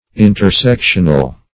intersectional - definition of intersectional - synonyms, pronunciation, spelling from Free Dictionary
Search Result for " intersectional" : The Collaborative International Dictionary of English v.0.48: Intersectional \In`ter*sec"tion*al\, a. Pertaining to, or formed by, intersections.